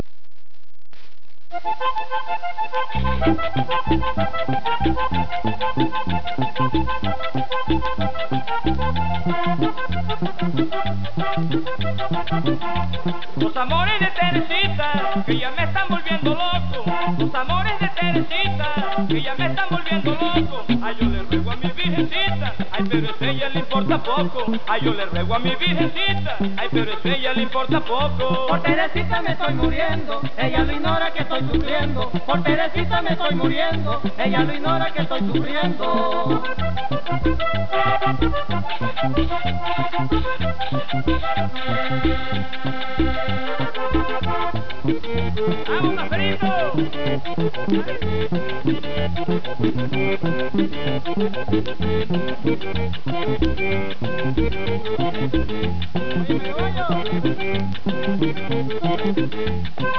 Merengue vallenato